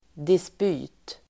Uttal: [disp'y:t]
dispyt.mp3